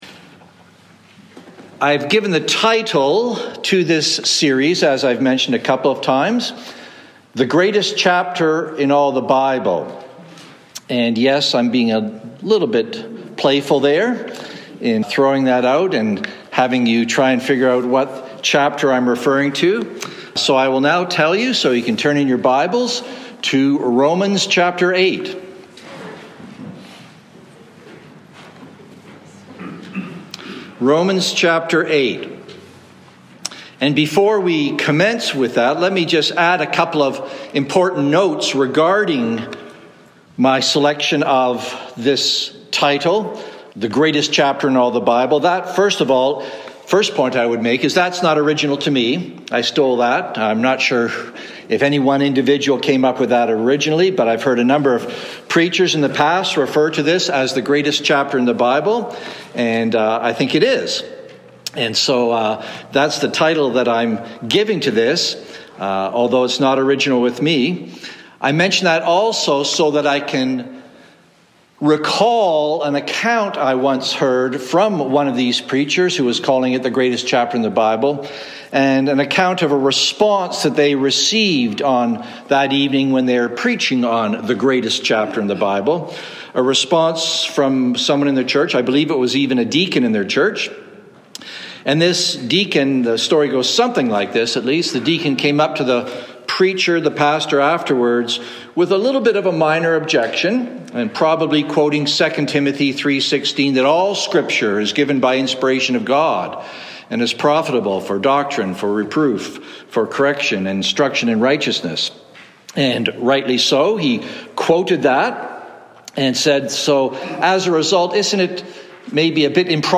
"No Condemnation" (Evening Service)
Download Download Reference Rom 8:1-2 Sermon Notes Mar 12-23PM.docx From this series "Who Shall Separate?"